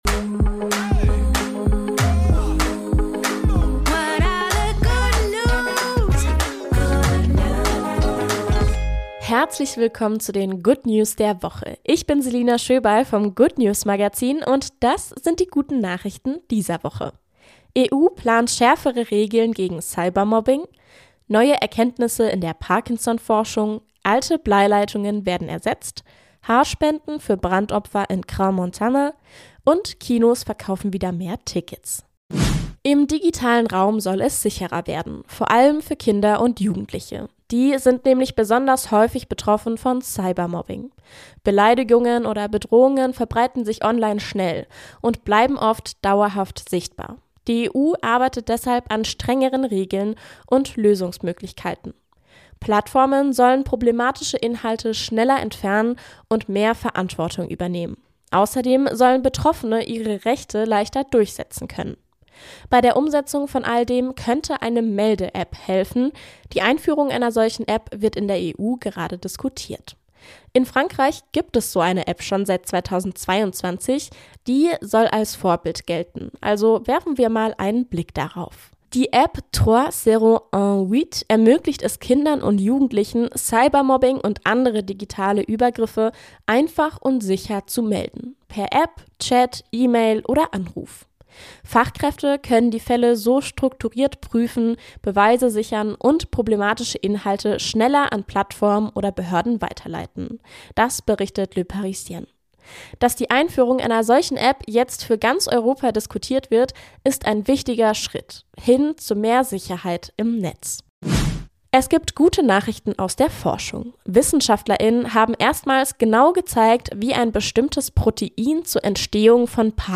Jede Woche wählen wir aktuelle gute Nachrichten aus und tragen sie